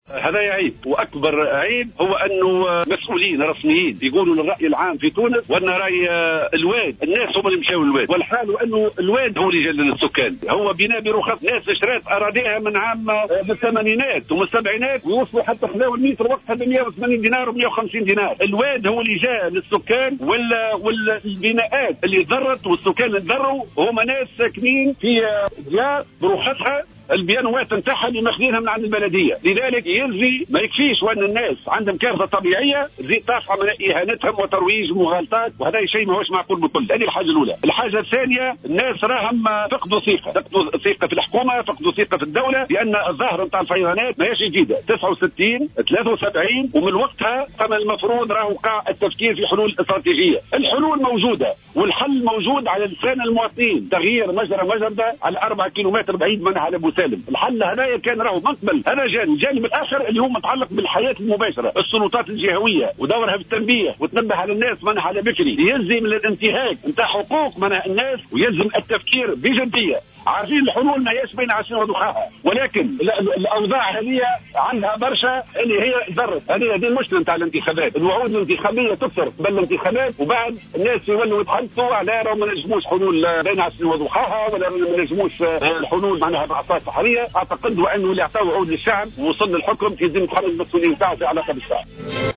واعتبر الهمامي، في تصريح للجوهرة أف أم، أن الحكومة تغالط الرأي العام بتحميل جزء من مسؤولية الكارثة للأهالي وهو ما وصفه "بالعيب" حيث أن بناءات الاهالي مرخصة وقانونية.